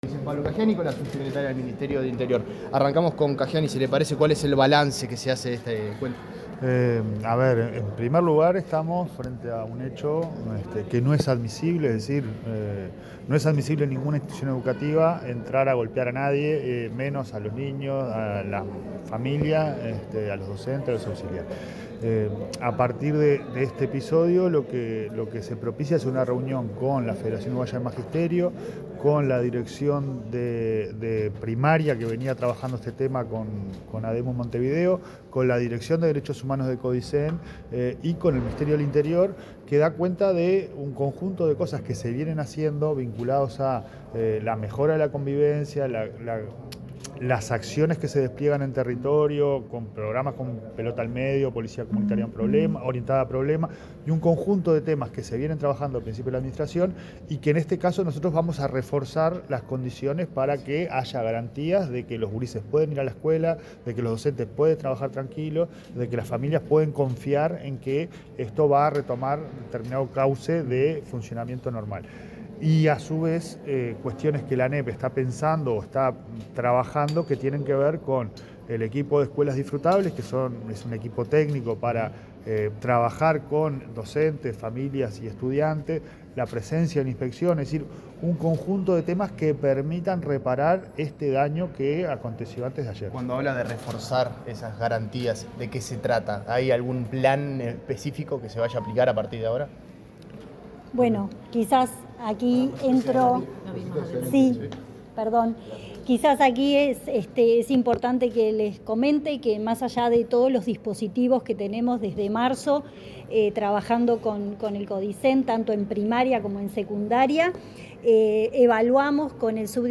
Declaraciones de la ministra Gabriela Valverde, y del presidente de ANEP, Pablo Caggiani
Declaraciones de la ministra Gabriela Valverde, y del presidente de ANEP, Pablo Caggiani 13/11/2025 Compartir Facebook X Copiar enlace WhatsApp LinkedIn Tras participar en la presentación de programa de escuelas de verano Sol y Convivencia, de Pelota al Medio a la Esperanza, la ministra interina de Interior, Gabriela Valverde, y el presidente de ANEP, Pablo Caggiani, dialogaron con la prensa.